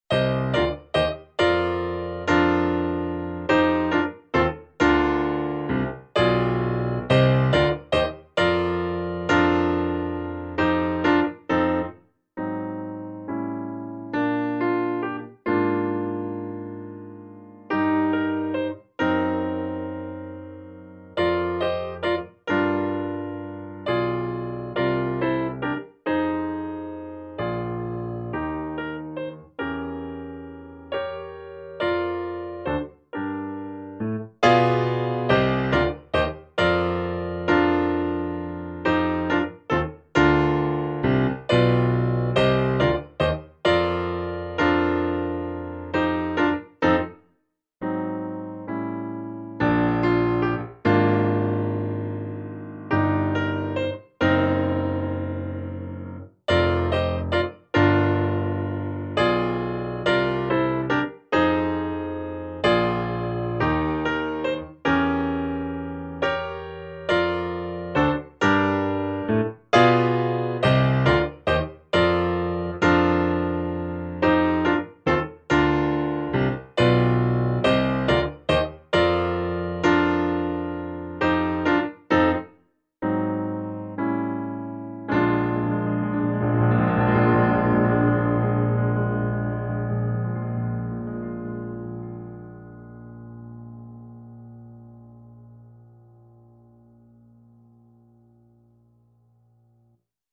Ein ruhiges und leicht zu spielendes Gospelstück.
Instrumentierung: Klavier solo